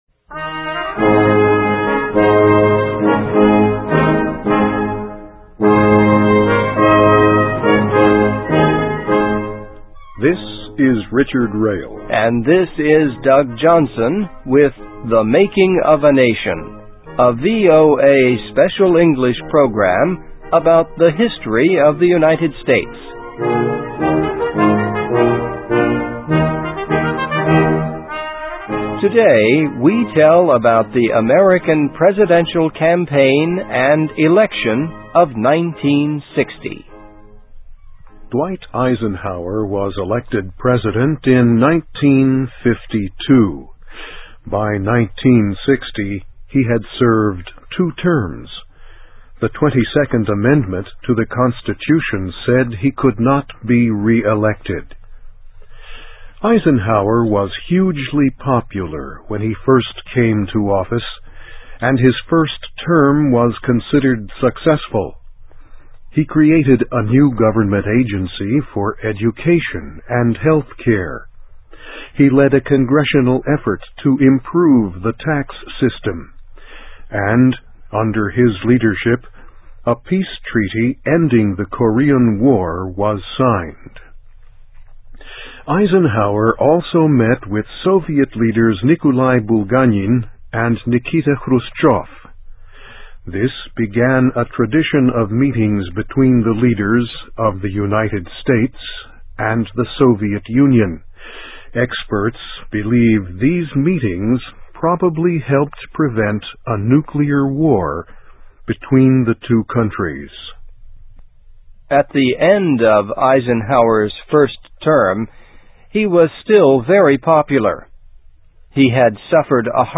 American History: Election of 1960 Brings Close Race Between Kennedy, Nixon (VOA Special English 2007-03-13)
Listen and Read Along - Text with Audio - For ESL Students - For Learning English